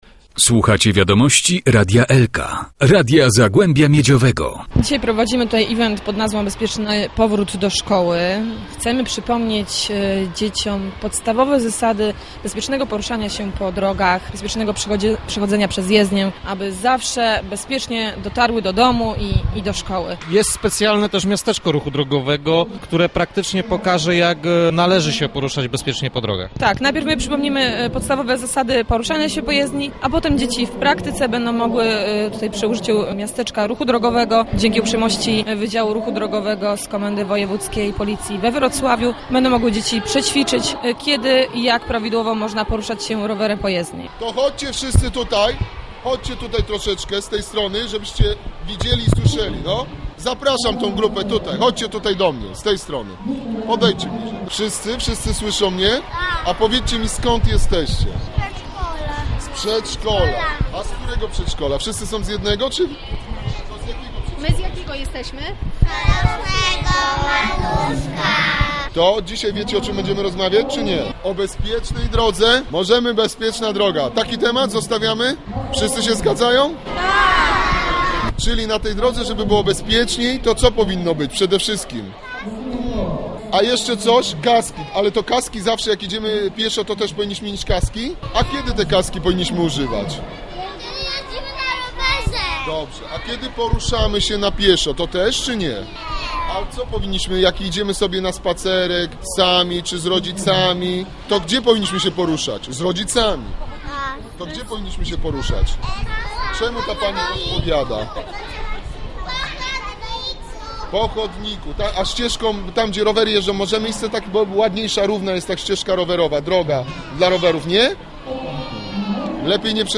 W atrium galerii stanęło miasteczko ruchu drogowego, były liczne konkursy z wiedzy o bezpieczeństwie i pokazy tresury psów.
atriumpolicja.mp3